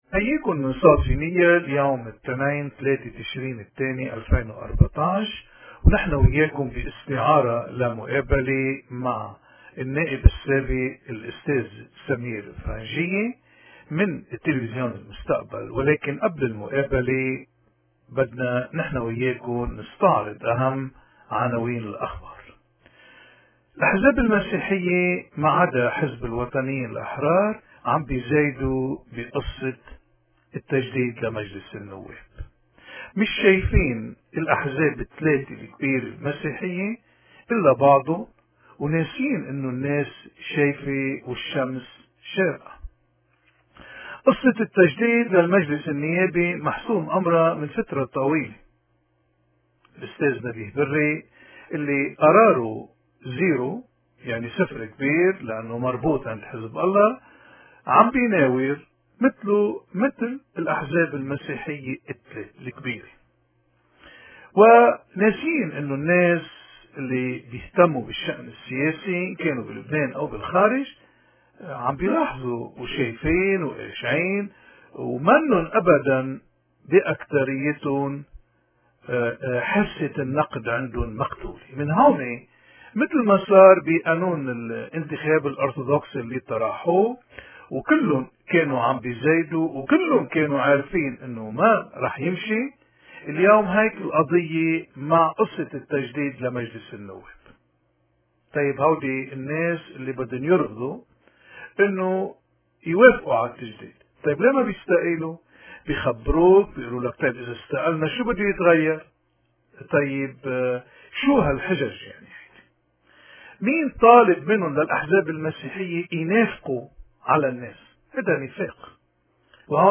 مقابلة مع السياسي المميز سمير فرنجية